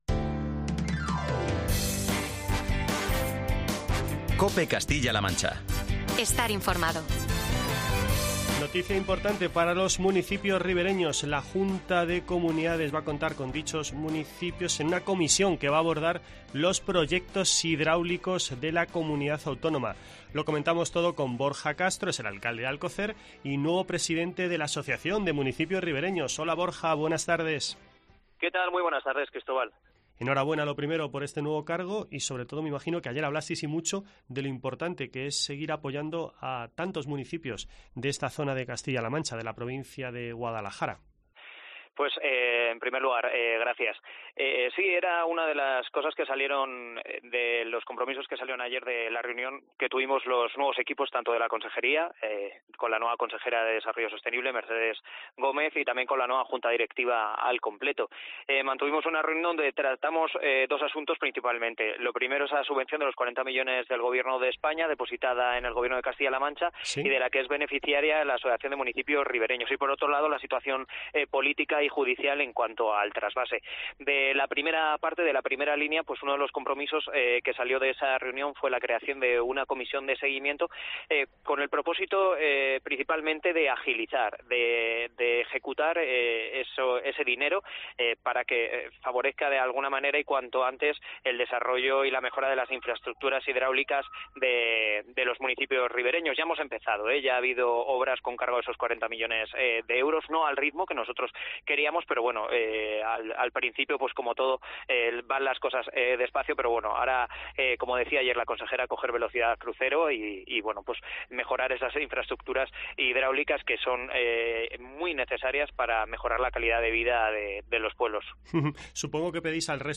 Charlamos de esta cuestión con Borja Castro, nuevo presidente de la Asociación de Municipios Ribereños y alcalde de la localidad guadalajareña de Alcocer